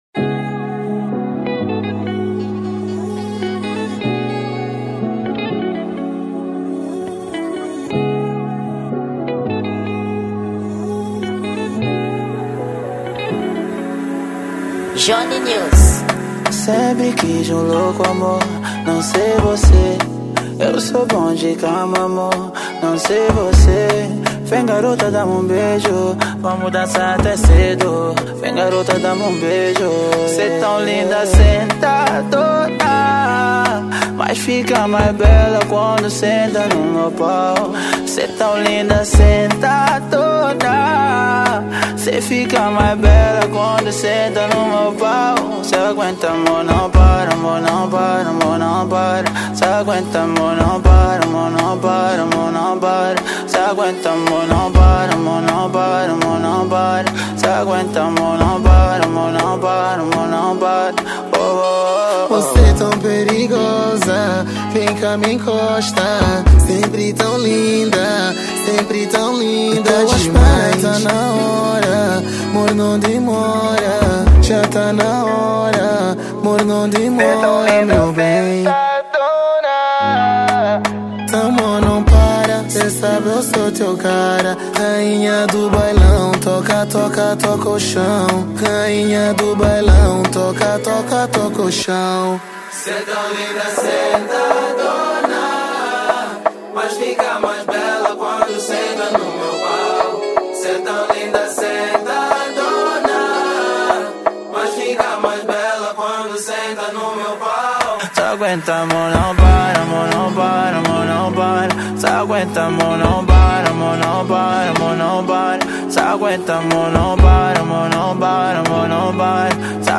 Gênero: Afro Funk